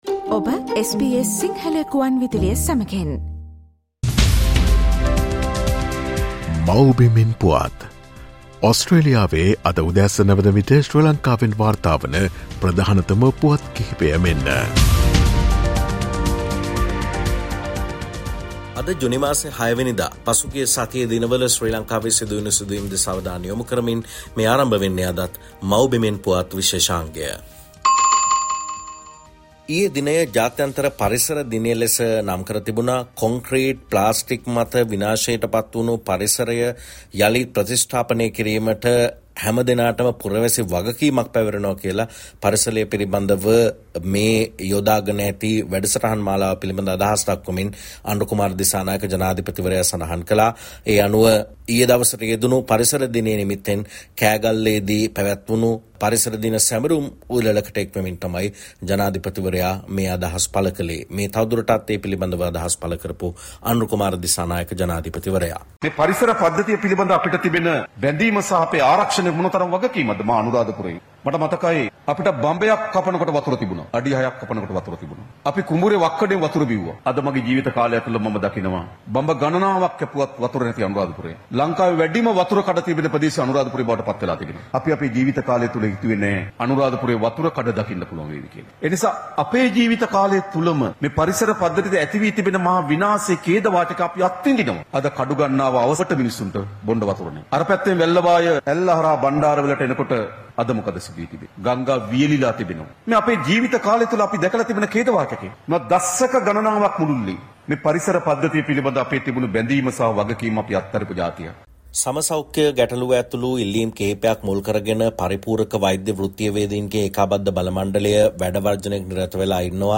ශ්‍රී ලංකාවේ සිට වාර්තා කරයි